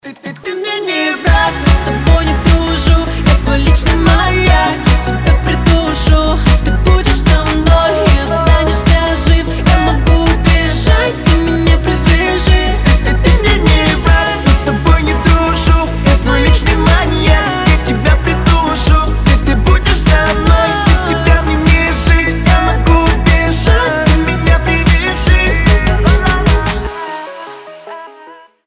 Танцевальные рингтоны
Дуэт , Ритмичные
Поп